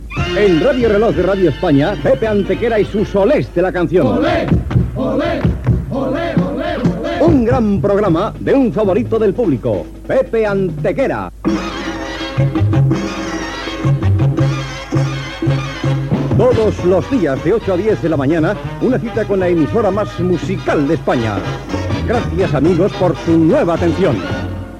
Promo del programa musical.